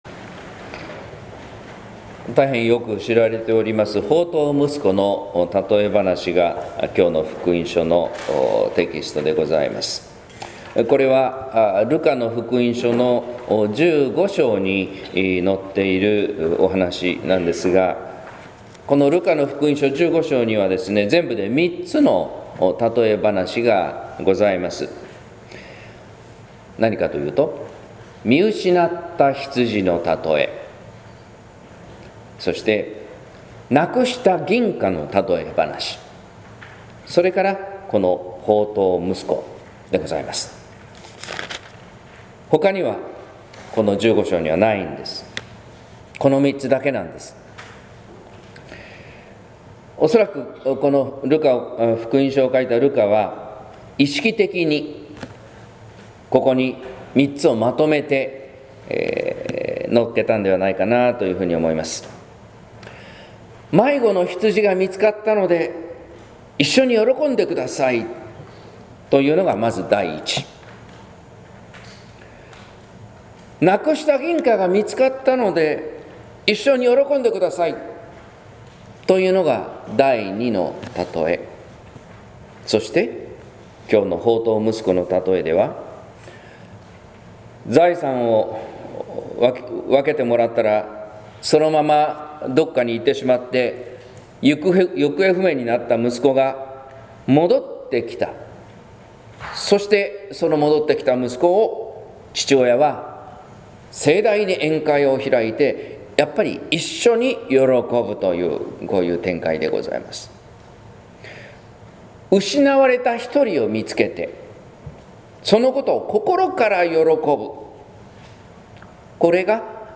説教「『放蕩息子』の読み方」（音声版）